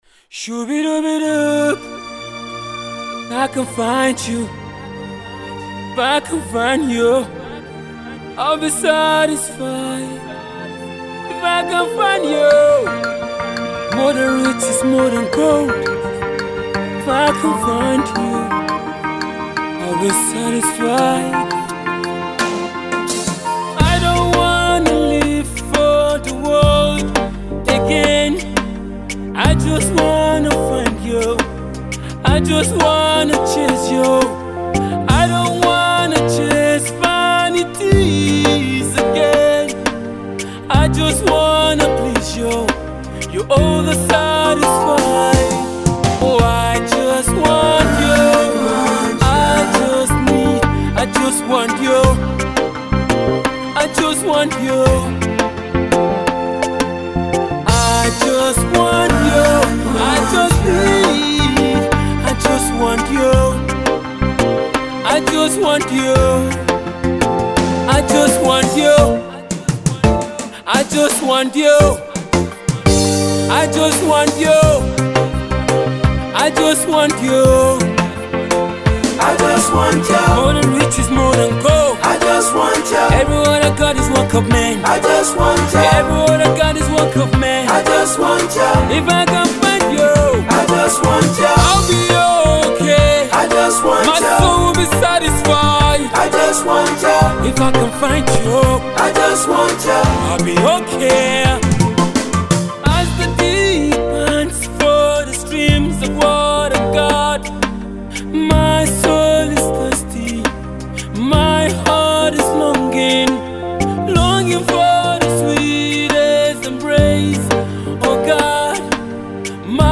Nigeria Soul music singer